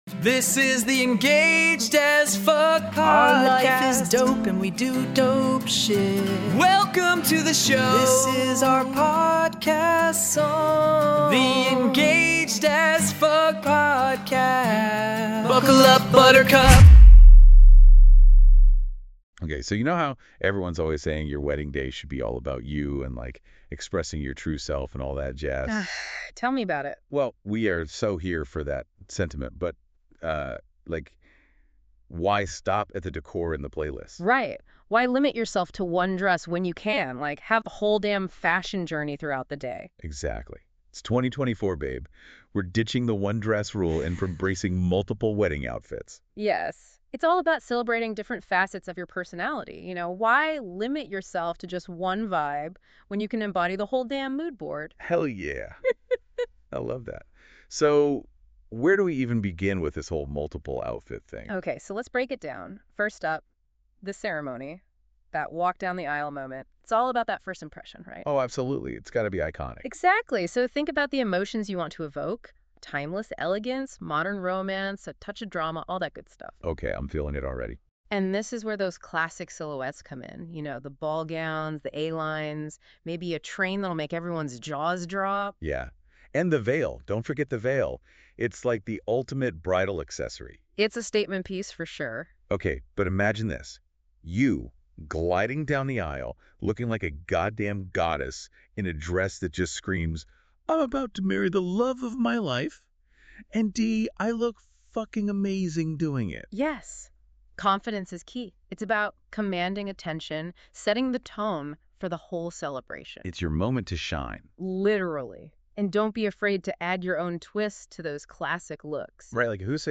Here’s the secret: We load all that killer magazine content into our AI system, which creates dynamic, unfiltered conversations inspired by the topics we’ve crafted.